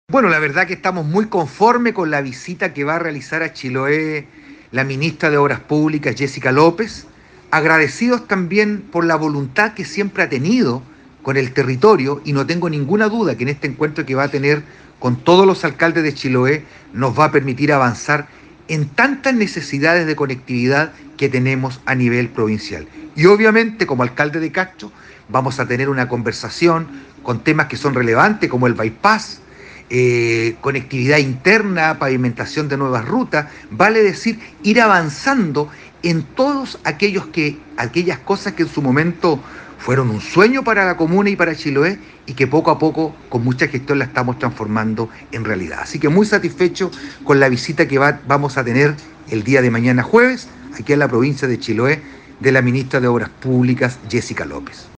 ALCALDE-VERA-SOBRE-VISITA-MINISTRA-MOP.mp3